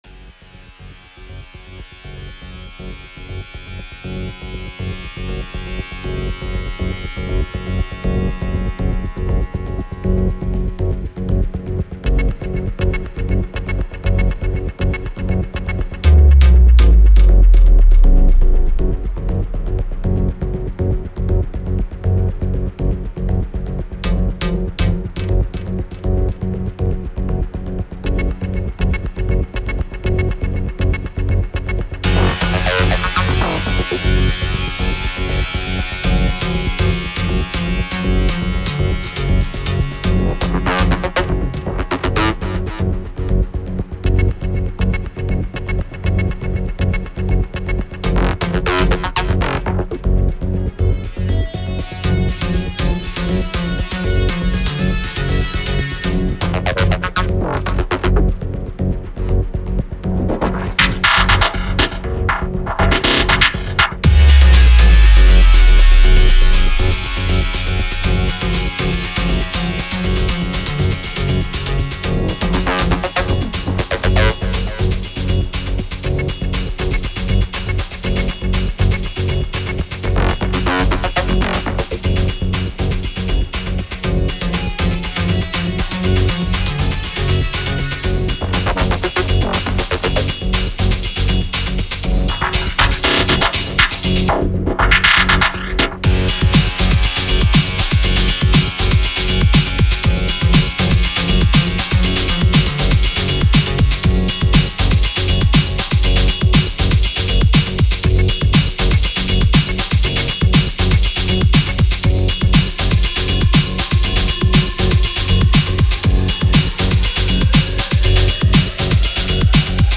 Genre : Electro